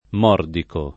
mordico [ m 0 rdiko ]